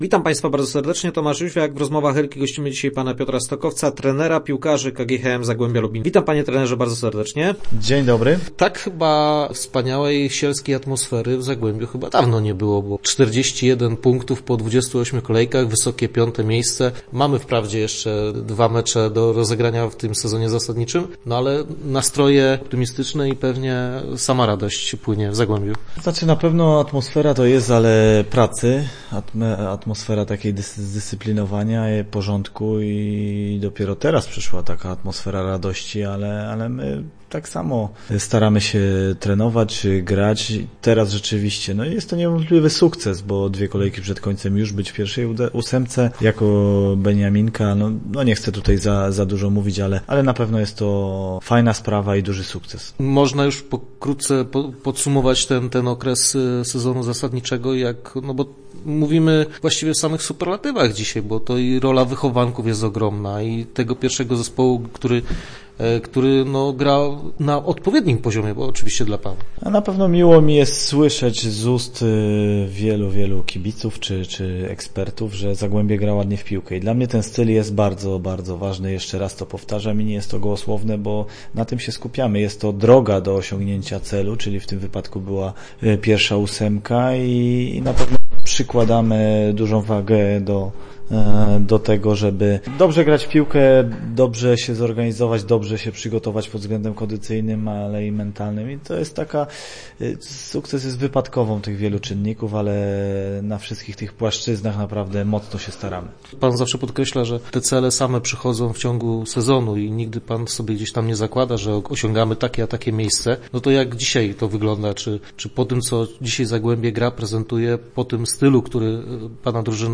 Start arrow Rozmowy Elki arrow Chcemy dawać kibicom jak najwięcej radości
Na co teraz stać Miedziowych? Naszym gościem był trener Zagłębia, Piotr Stokowiec.